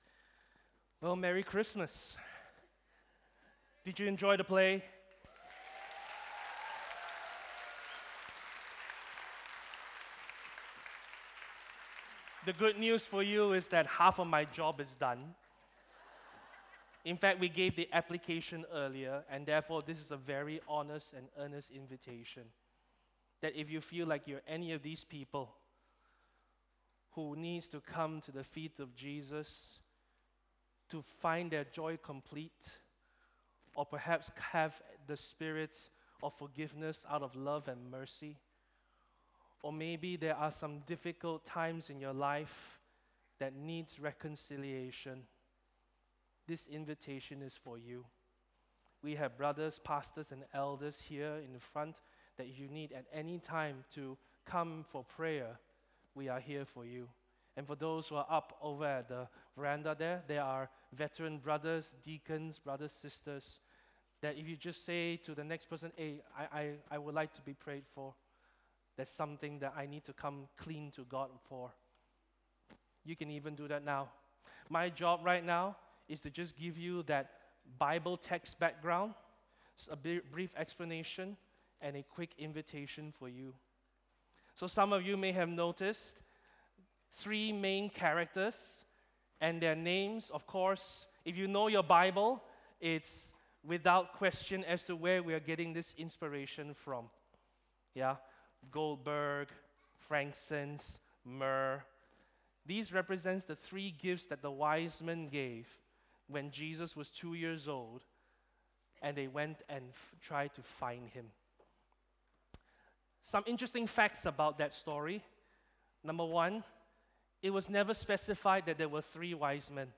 “Come to me,” Jesus’ invitation for you! (Christmas Day Message)